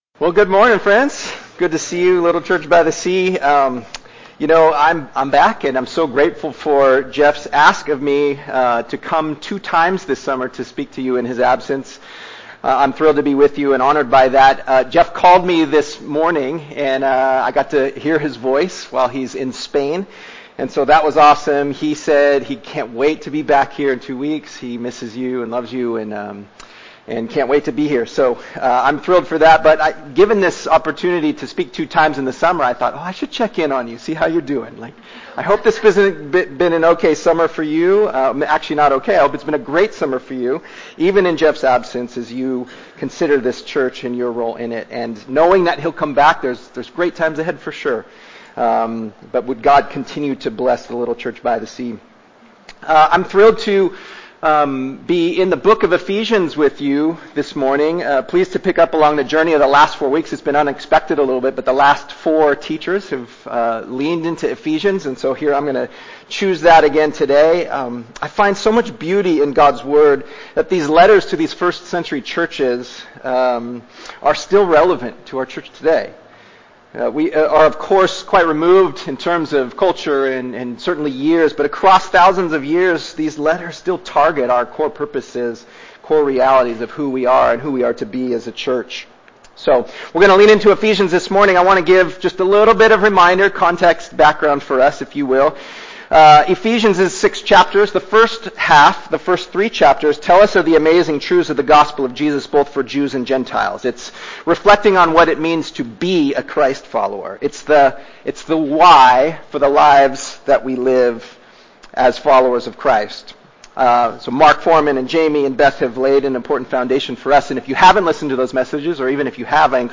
Series: Ordinary Time